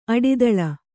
♪ aḍidaḷa